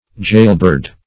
jailbird \jail"bird`\, jail bird \jail" bird`\